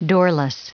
Prononciation du mot doorless en anglais (fichier audio)
Prononciation du mot : doorless